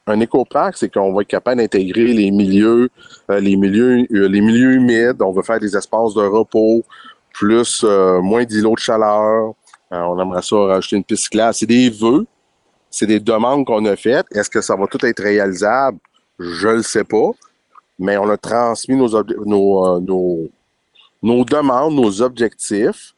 Le maire, Gilles Jr Bédard, a donné les grandes lignes de sa vision pour le parc industriel de 3,6 millions de pieds carrés.